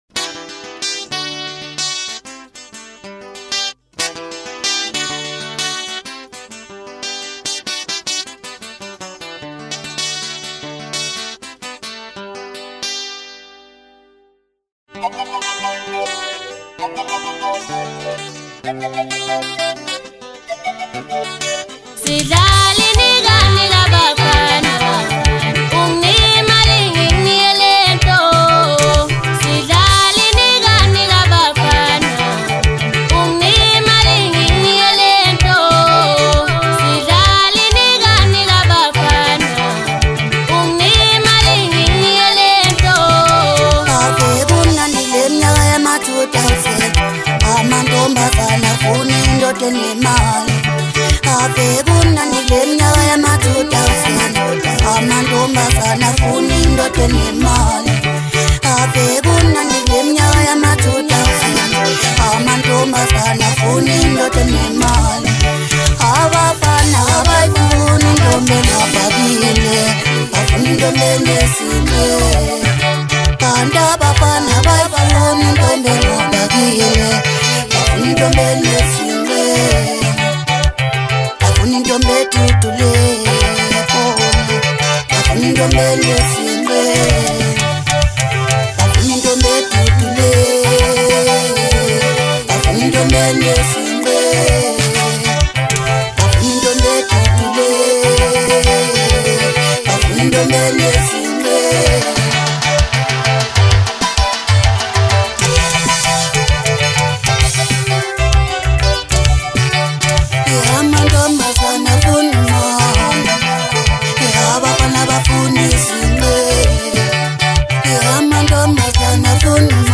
December 29, 2025 admin Maskandi 0